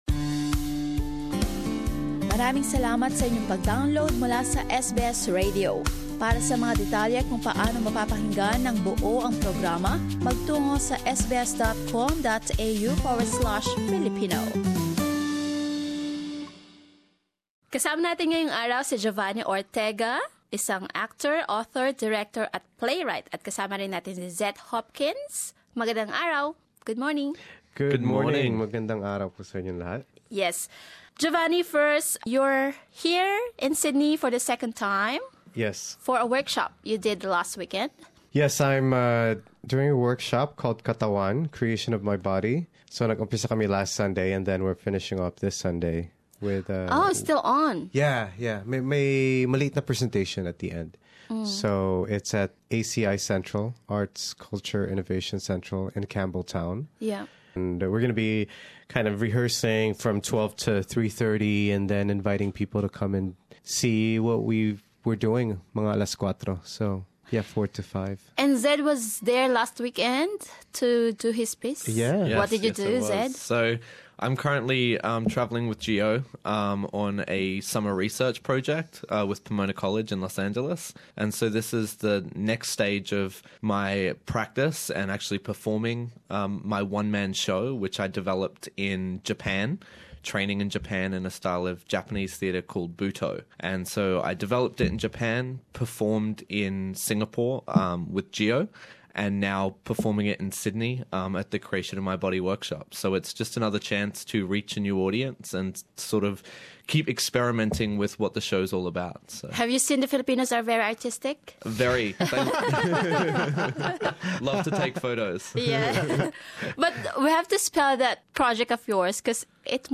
Video of the interview available below: Share